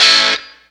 Track 02 - Guitar Stab OS 09.wav